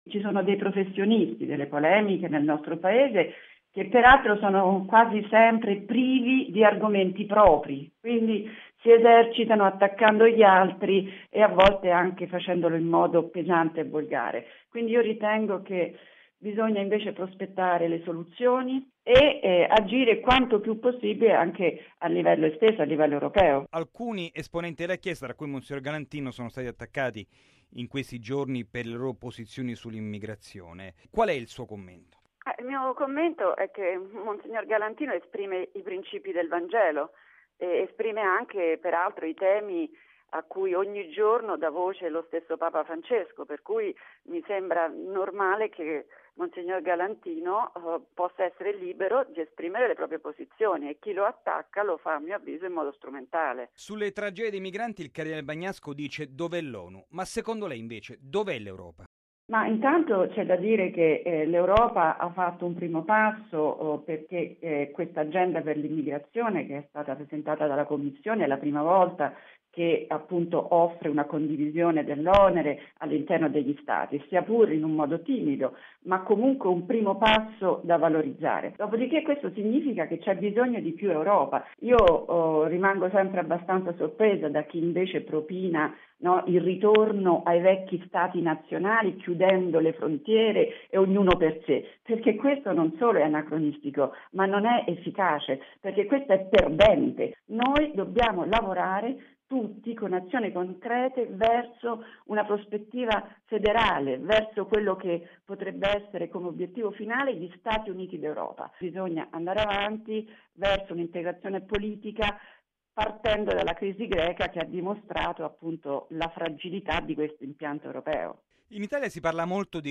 L'intervista